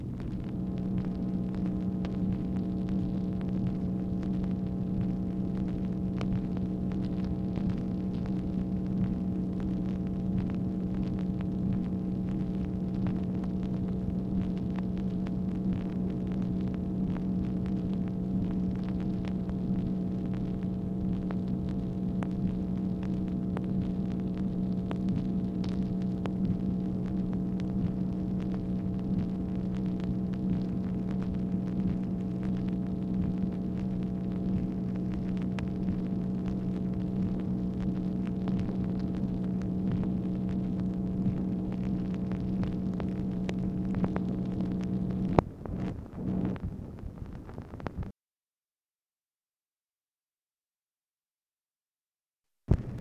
MACHINE NOISE, May 13, 1965
Secret White House Tapes | Lyndon B. Johnson Presidency